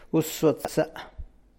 uaszuatsa[ua’szuatza’]